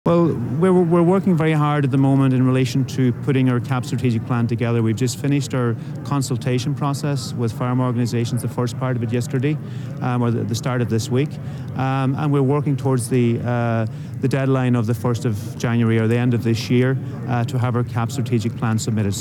Speaking as he attended an informal meeting of EU Agriculture Ministers in Slovenia, Donegal Deputy and Minister for Agriculture Charlie McConalogue says the consultation process is underway: